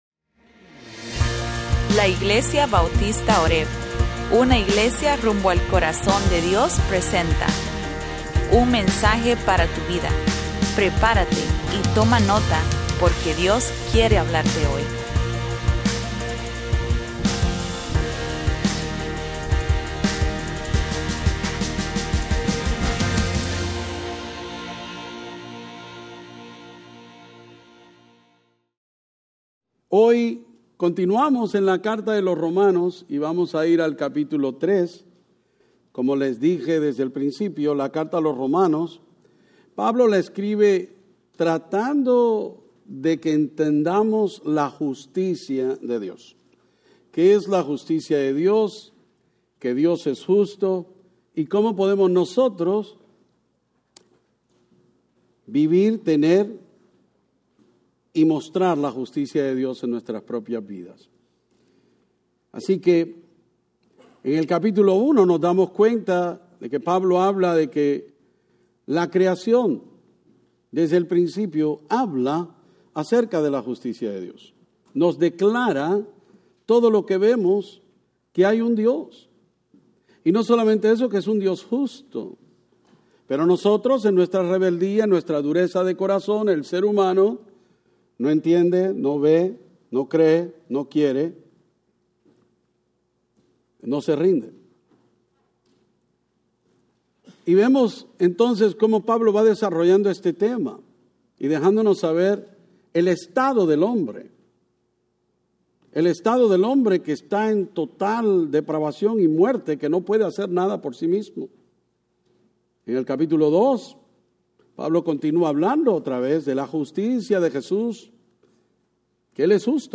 Sermones-5_19_24-spanish.mp3